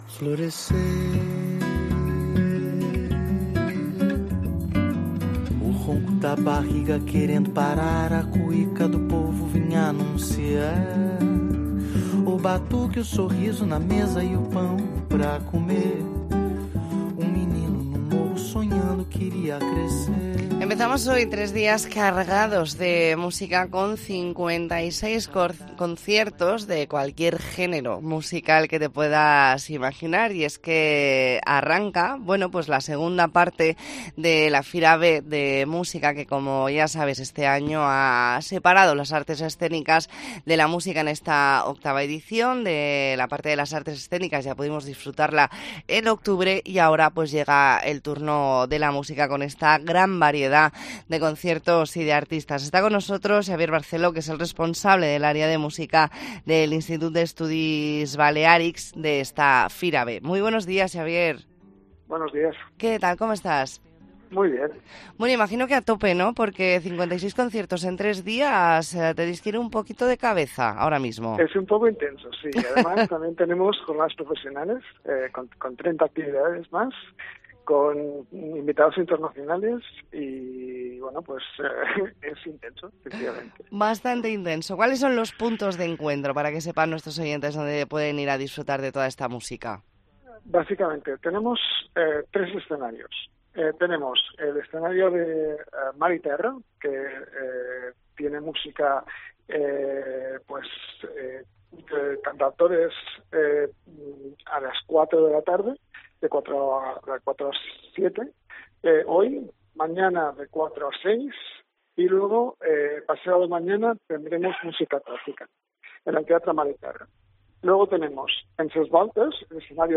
E ntrevista en La Mañana en COPE Más Mallorca, jueves 2 de noviembre de 2023.